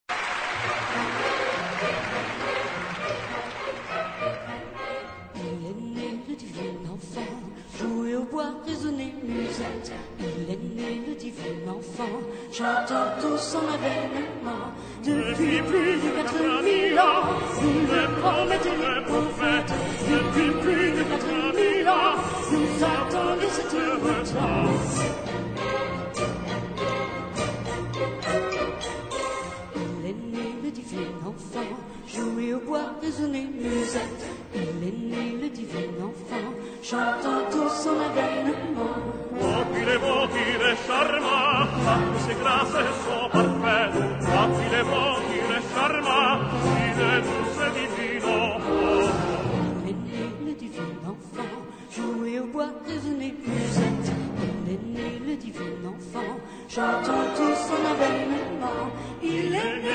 key: B-major